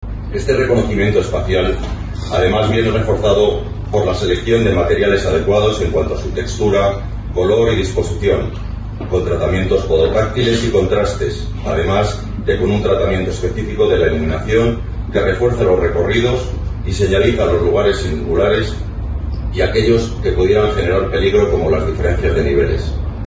El pasado 3 de octubre se produjo el acto de colocación de la primera piedra de un nuevo gran edificio que se convertirá en el mayor centro de atención a la discapacidad de toda la región.